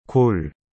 Isso mesmo, a pronúncia é praticamente a mesma do português!A razão para essa semelhança está na origem: a palavra vem diretamente do inglês “goal” e foi adaptada ao alfabeto coreano, o 한글 (hangul).Neste artigo, você vai descobrir como usar essa palavra no dia a dia, entender o contexto cultural por trás dela e aprender várias frases práticas.
골.mp3